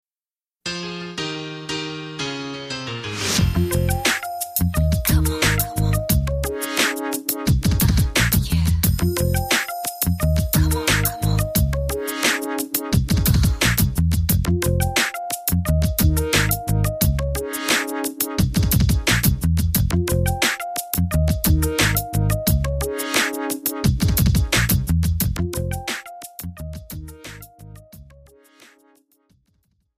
This is an instrumental backing track cover.
• Key – Fm
• With Backing Vocals
• No Fade